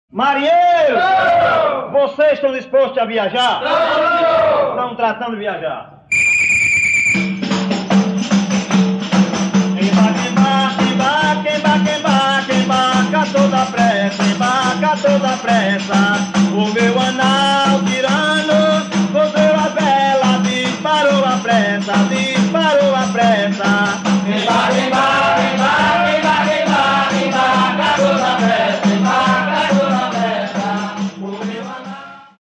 Chegança de marujo